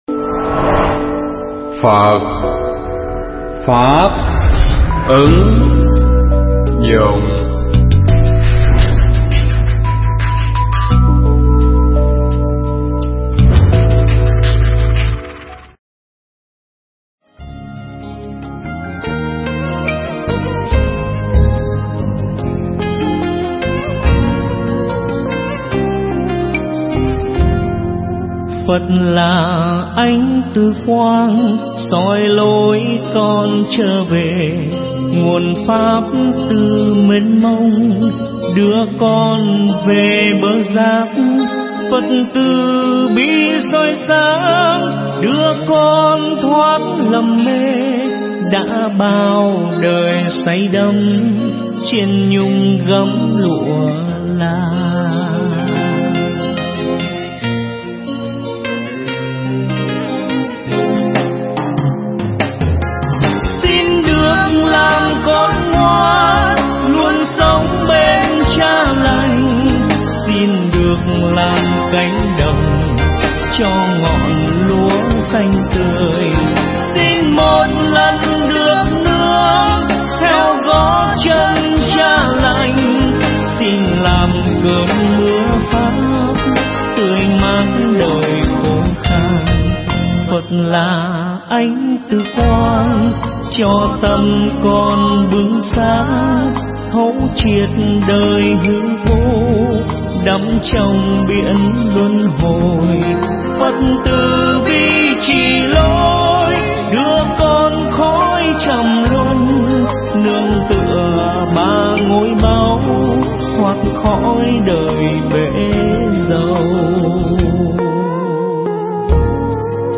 Thuyết pháp Bốn Pháp Nương Tựa
tại chùa Đức Hoa (Bình Dương)